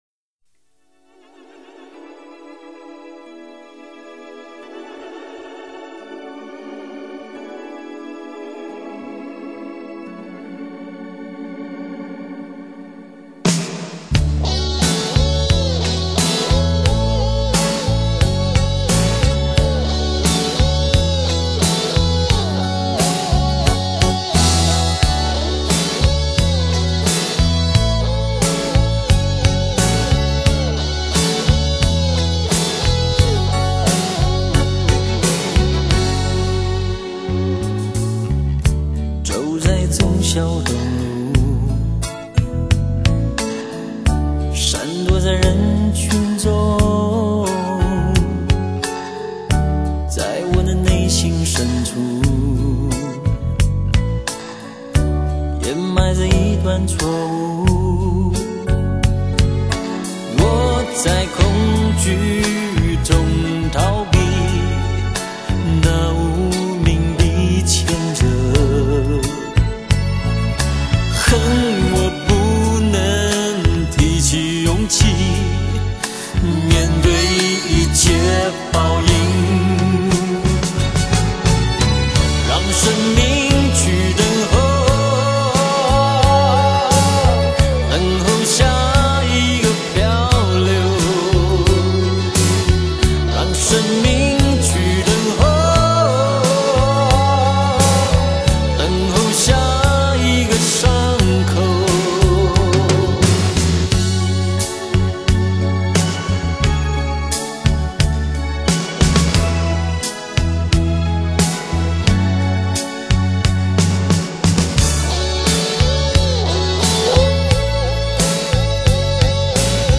声音圆润、细腻、生动……声场宽厚、原始、真实……浑然天成般动人、耐听……